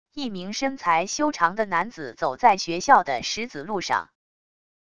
一名身材修长的男子走在学校的石子路上wav音频